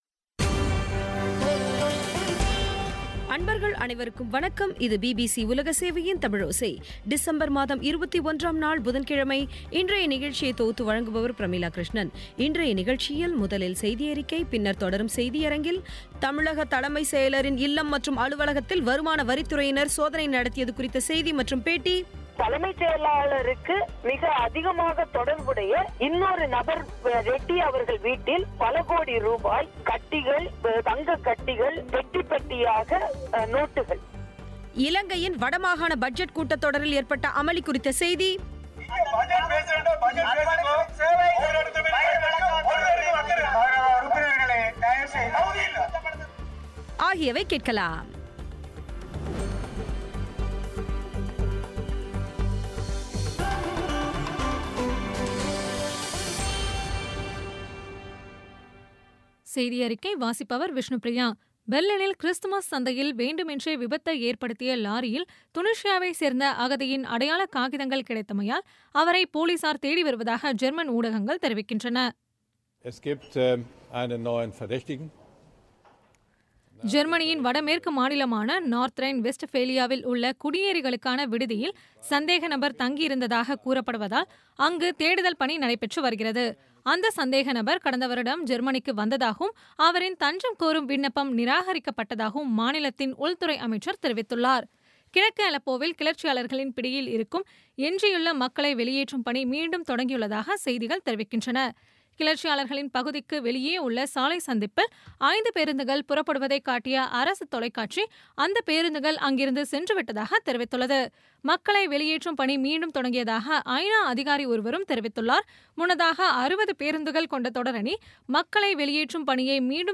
இன்றைய தமிழோசையில், முதலில் செய்தியறிக்கை, பின்னர் தொடரும் செய்தியரங்கத்தில்,தமிழக தலைமைச் செயலரின் இல்லம் மற்றும் அலுவலகத்தில் வருமான வரித்துறை சோதனை நடத்தியது குறித்த செய்தி மற்றும் பேட்டி இலங்கையின் வடமாகாண பட்ஜெட் கூட்டத்தொடரில் ஏற்பட்ட அமளி குறித்த செய்தி ஆகியவை கேட்கலாம்